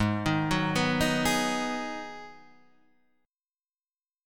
G#m7 chord